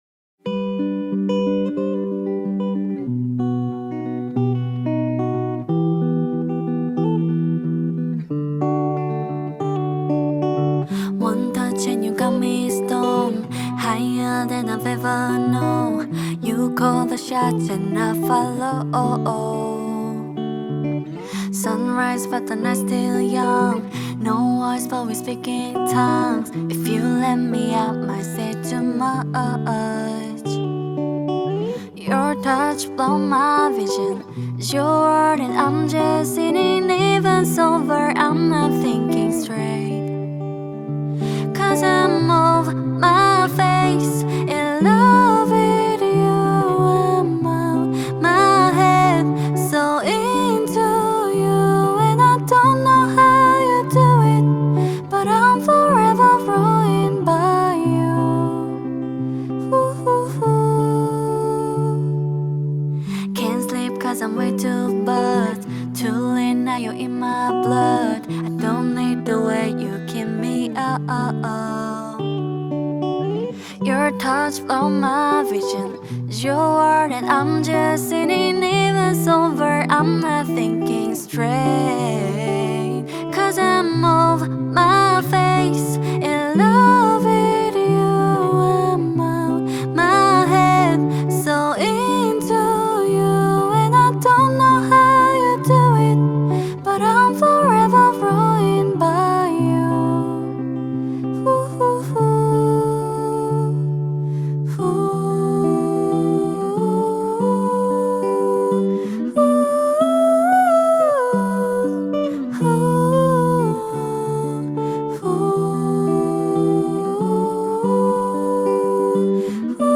منبع صداش از بهشتههه از بس که آرامش بخشه